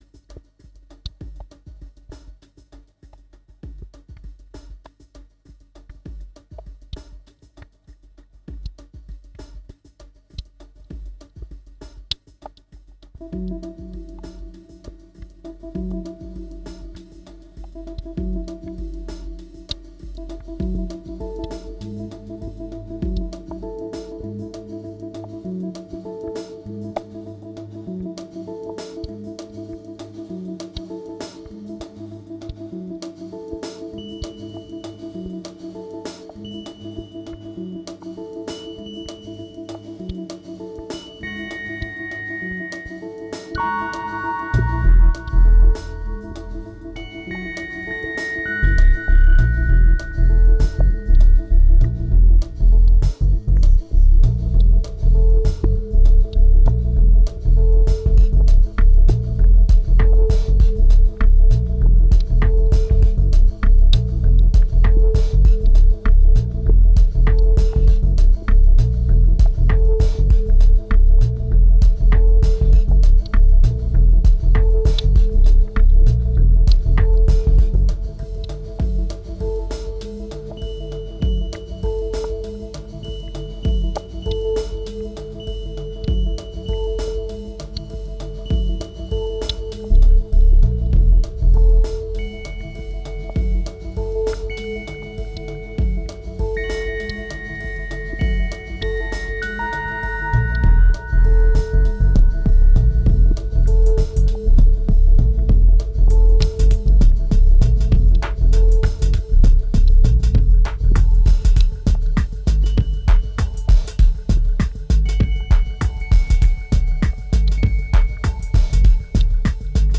Drowning under massive FX, there still is a part of truth.